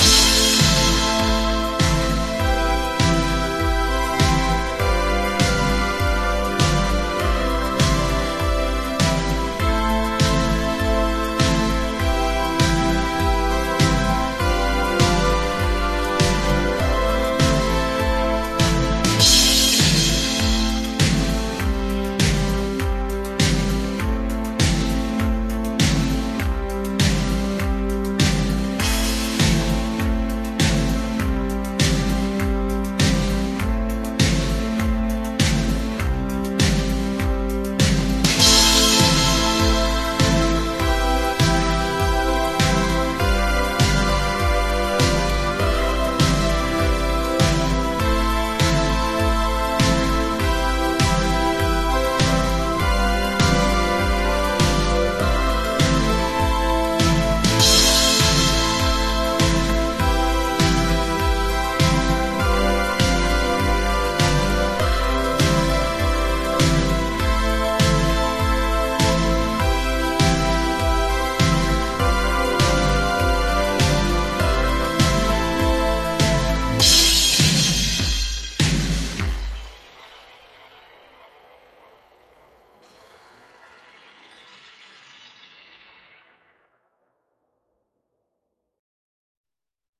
synthpop_0422_1.mp3